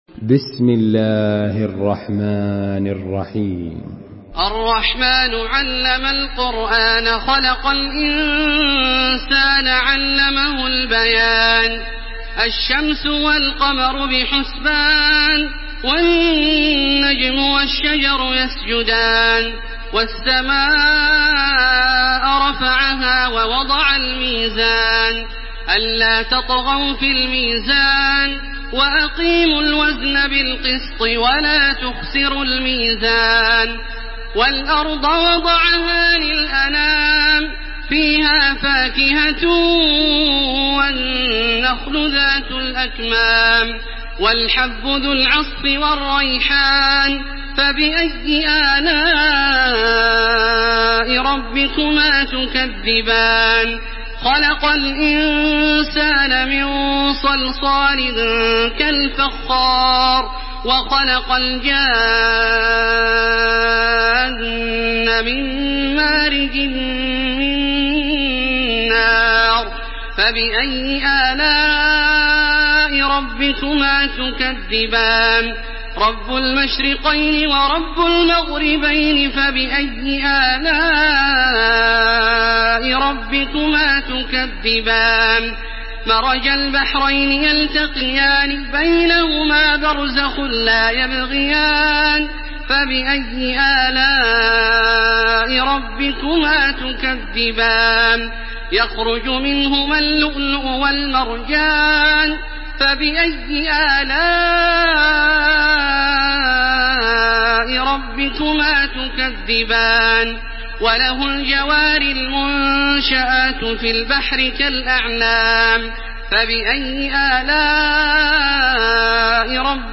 Surah Ar-Rahman MP3 by Makkah Taraweeh 1429 in Hafs An Asim narration.
Murattal